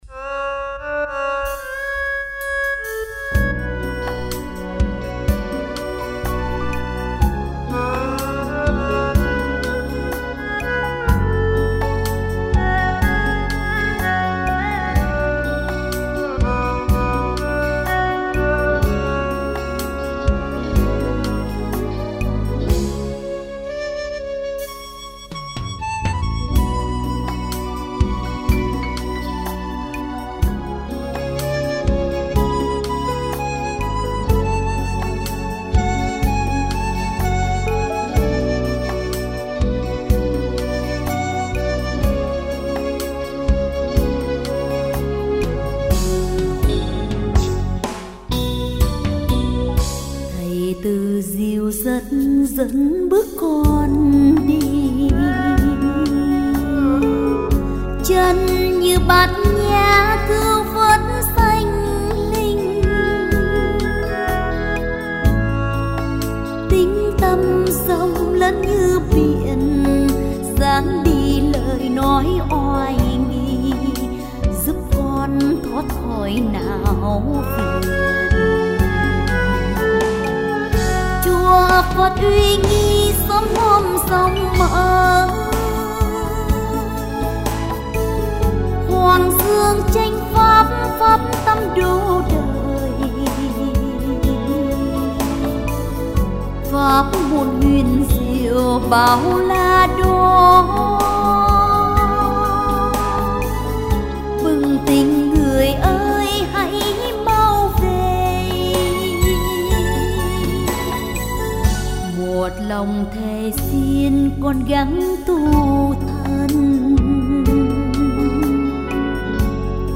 Category: Tân Nhạc